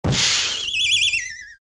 Drop, étoiles overhead (comique):